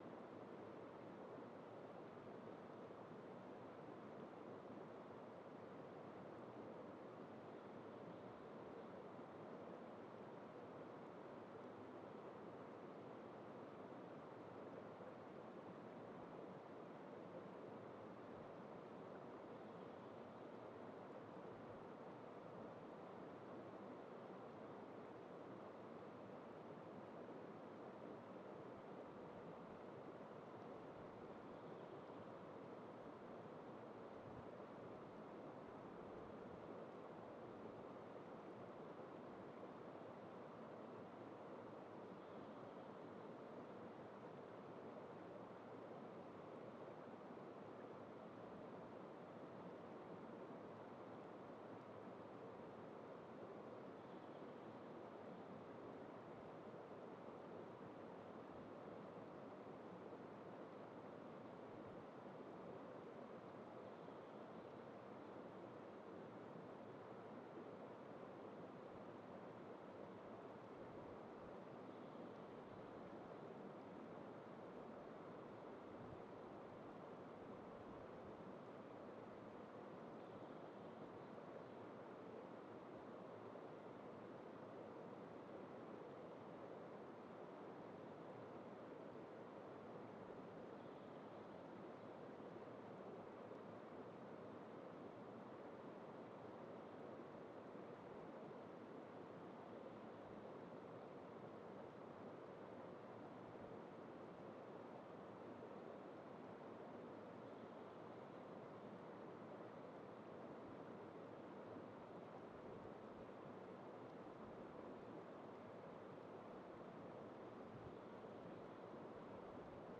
Quellrauschen125.mp3